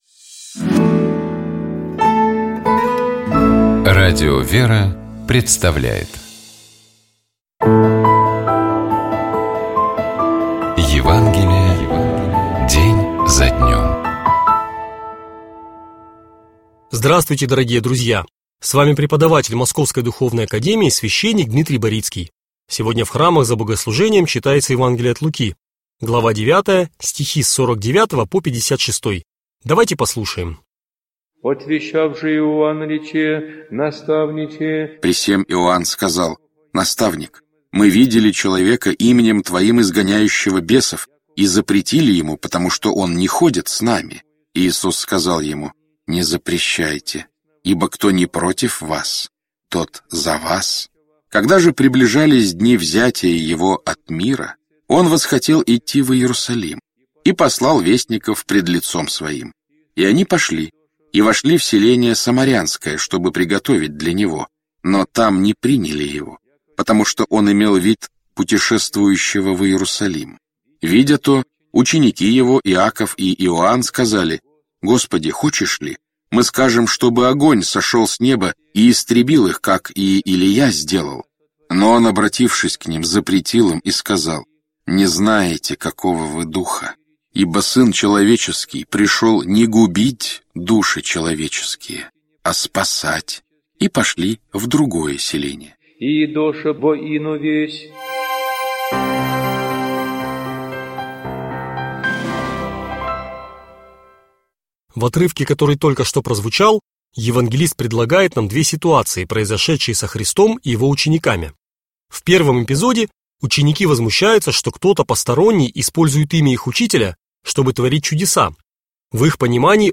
Читает и комментирует cвященник